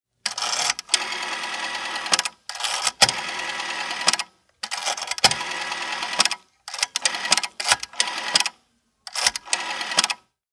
Tischtelefon Fg tist 264 b
Trommelwählscheibe
0038_Trommelwaehlscheibe.mp3